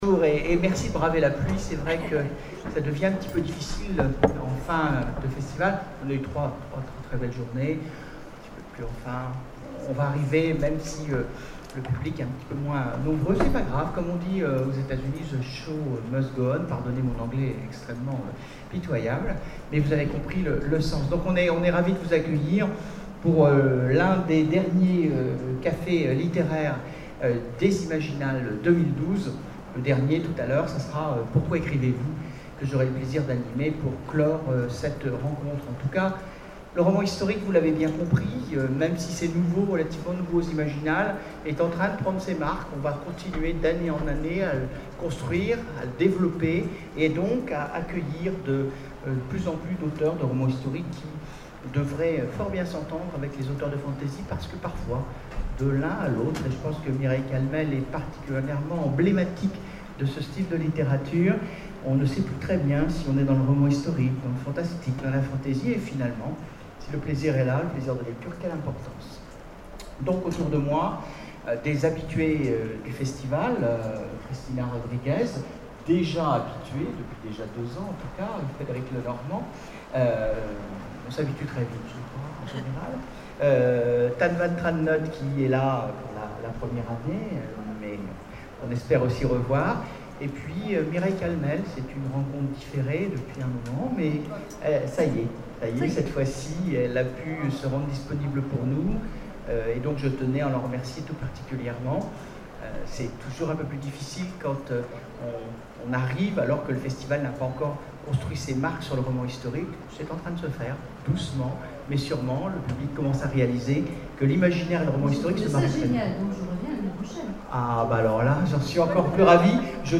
Imaginales 2012 : Conférence Le roman historique...